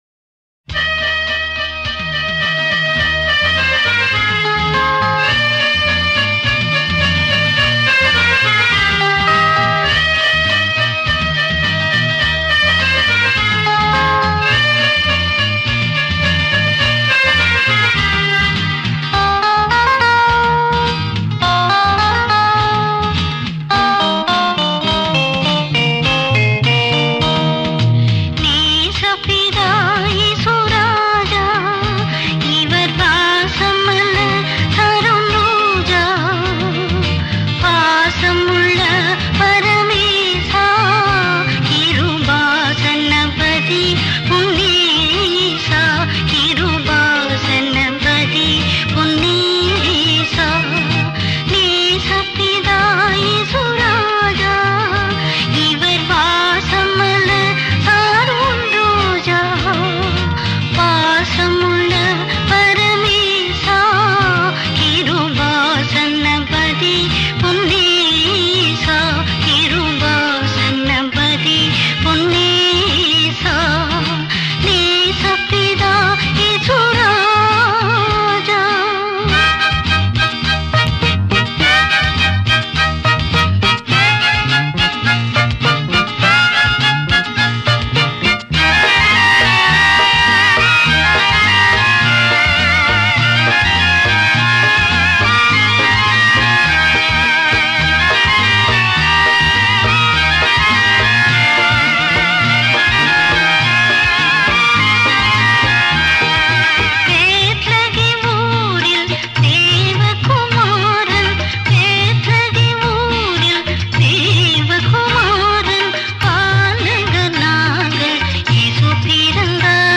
Royalty free Christian music.